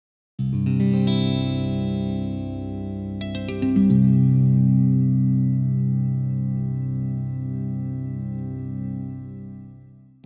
C-G-C-G-C-E
Enjoy the huge, loose low end!